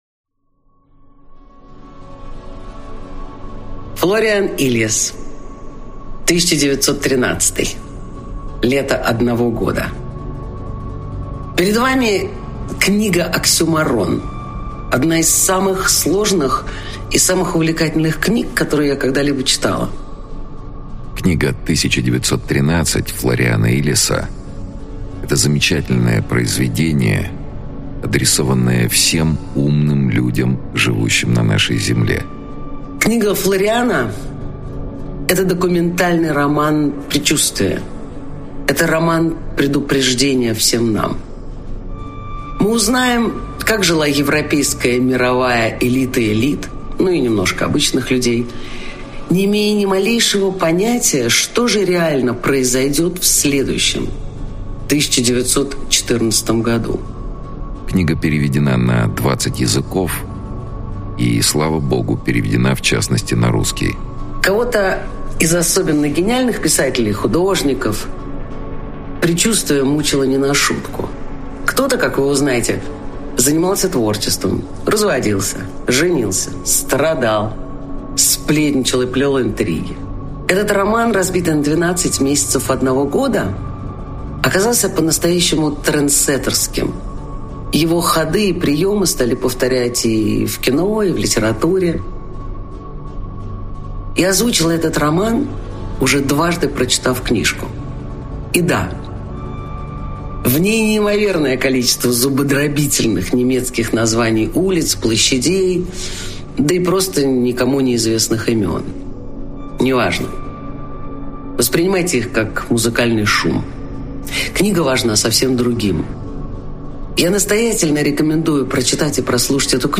Aудиокнига 1913.